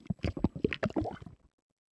喝水zth070518.wav
通用动作/01人物/02普通动作类/喝水zth070518.wav
• 声道 單聲道 (1ch)